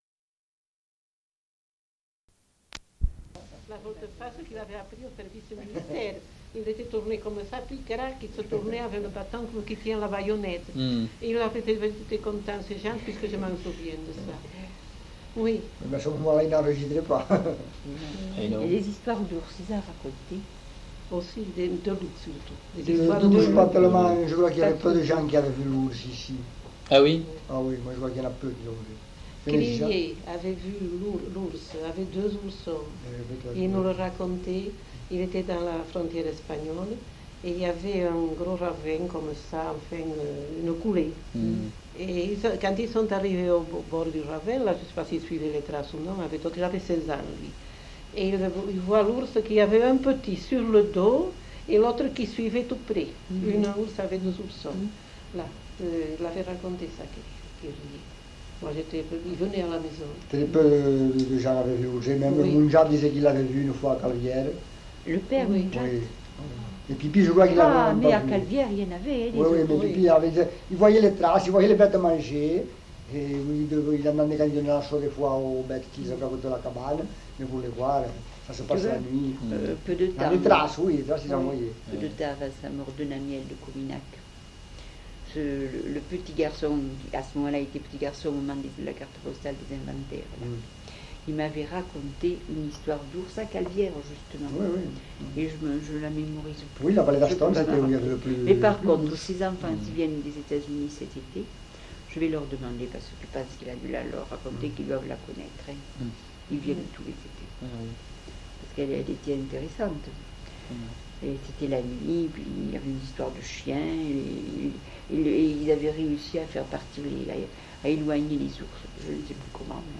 Aire culturelle : Couserans
Lieu : Aulus-les-Bains
Genre : témoignage thématique